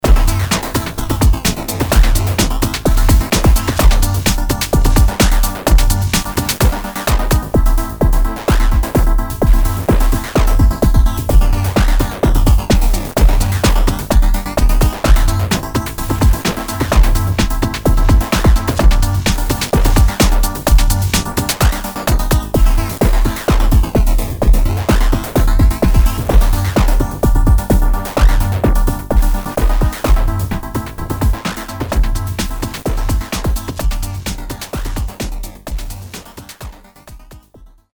WhiteNoise Mono Pseudo Random Noise
TPDFNoise Mono dither noise, triangular powerdistribution
VCO1 Oscillator with audio input for frequency control
Aliaser Stereo Downsampling (Sample&Hold) distortion effect